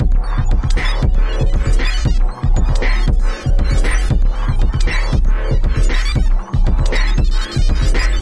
描述：抽象故障效果/使用Audacity和FL Studio 11制作
Tag: 电气 怪异 音响设计 效果 设计 毛刺 随机 摘要 科幻 声音